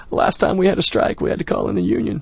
b_strike_chat.wav